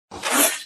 Thrall-Claw-Attack-Sound-Effect-Fifth-Variation